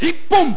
The Victory announcement by the judge (